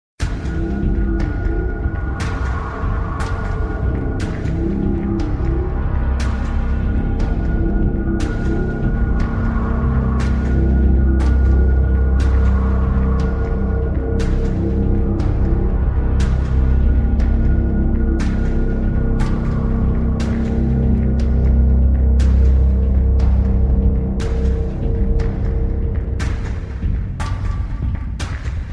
Другие рингтоны по запросу: | Теги: Напряженная музыка
Категория: Музыка из фильмов ужасов